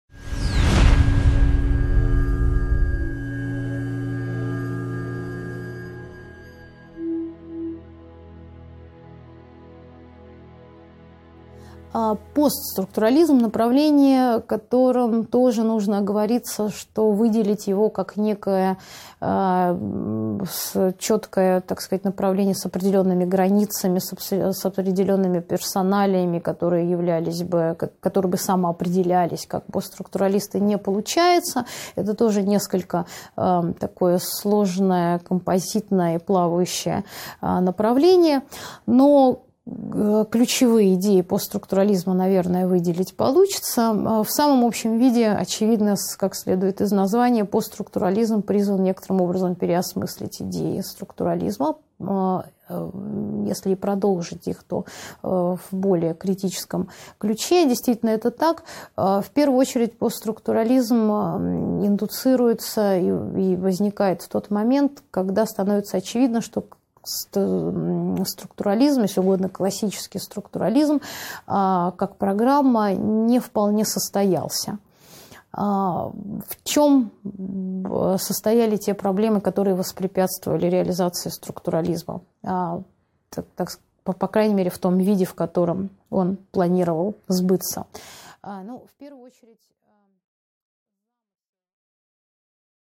Аудиокнига 15.7 Постструктурализм | Библиотека аудиокниг